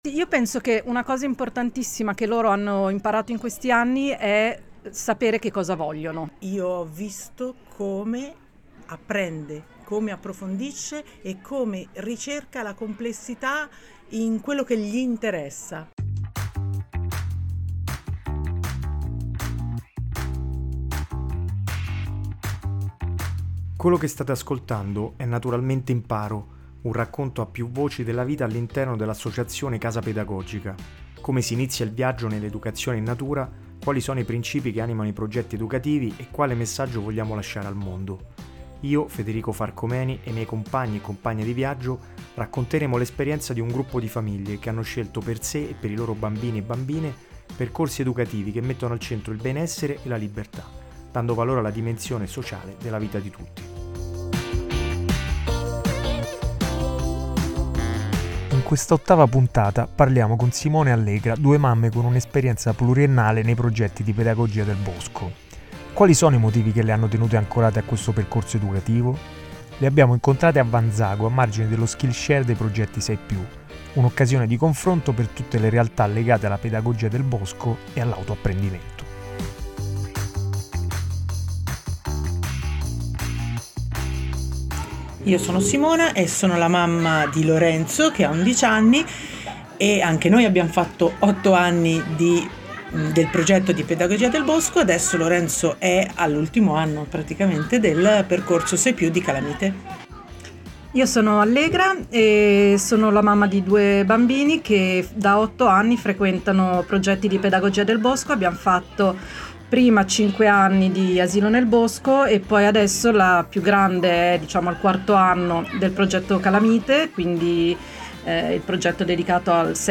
Le abbiamo incontrate a Vanzago, a margine dello skillshare dedicato ai progetti 6+, e le abbiamo interrogate in merito alla loro lunga esperienza all’interno del progetto: quali sono i motivi che le hanno tenute ancorate a questo percorso educativo?